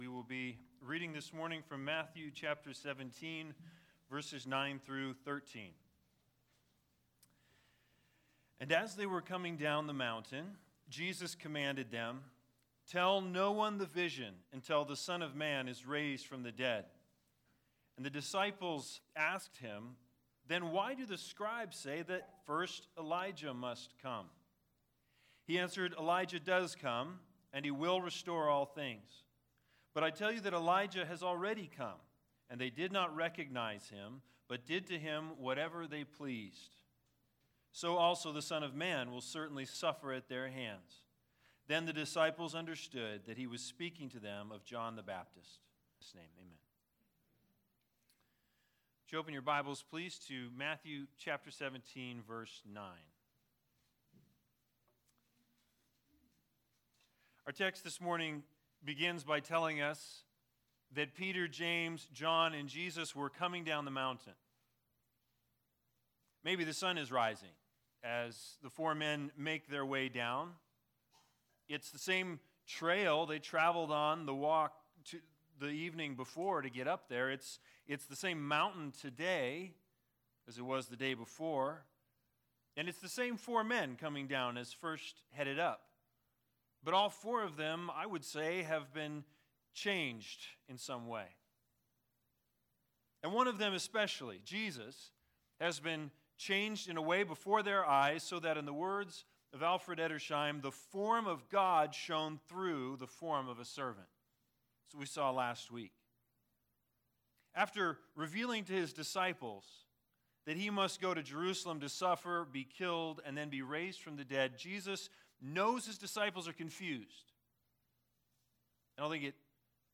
Matthew 17:9-13 Service Type: Sunday Sermons The Big Idea